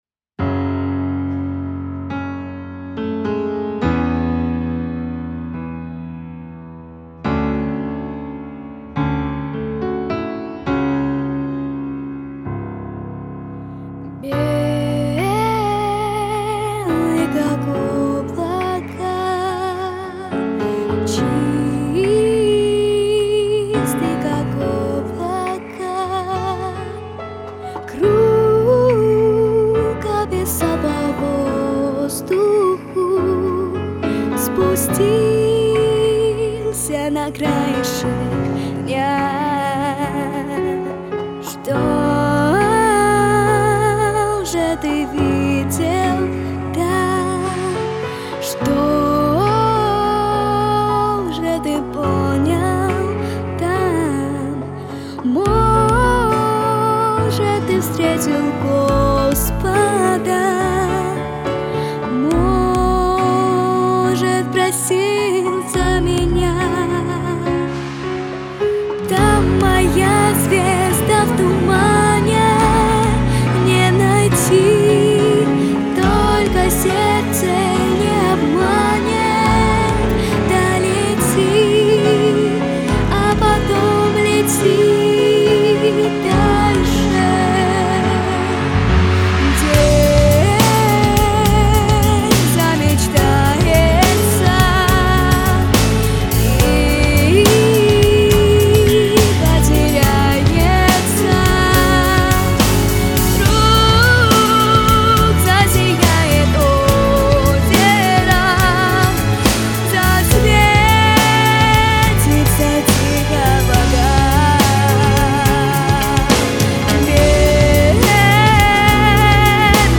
• Категория: Детские песни
Детская песня